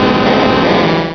pokeemerald / sound / direct_sound_samples / cries / onix.aif
onix.aif